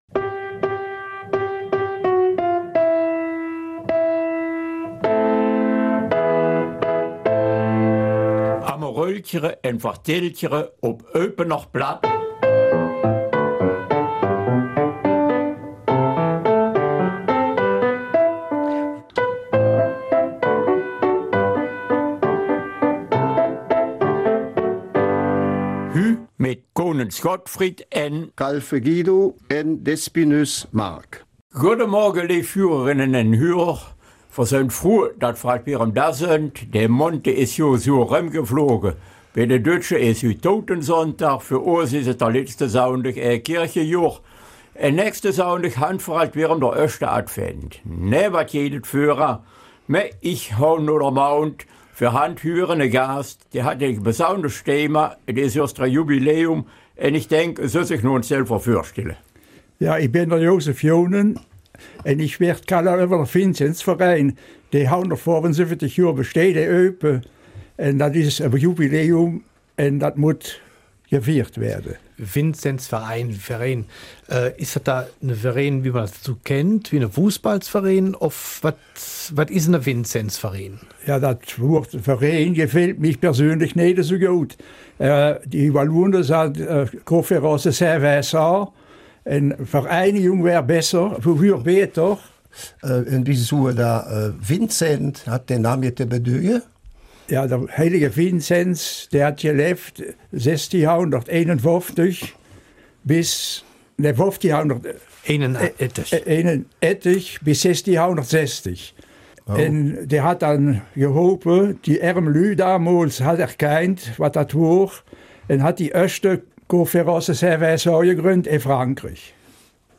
Eupener Mundart: Vinzenzverein - Hilfe, die von Herzen kommt